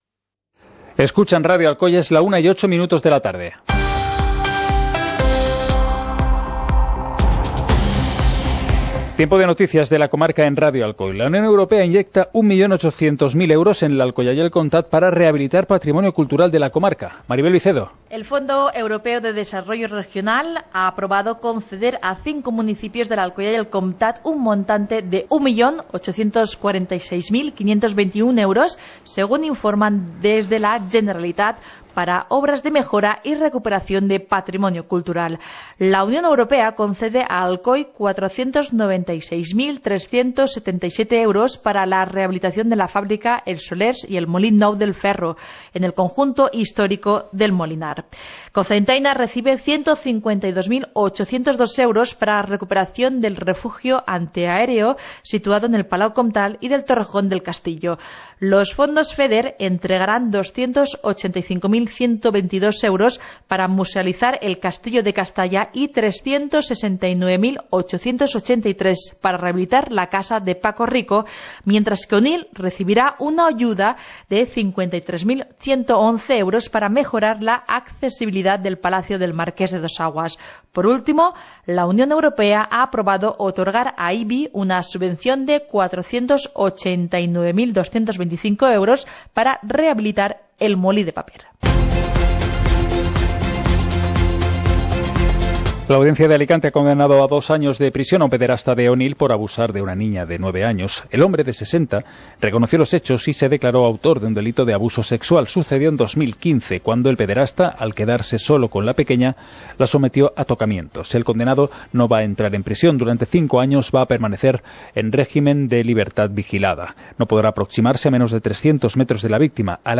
Informativo comarcal - lunes, 15 de octubre de 2018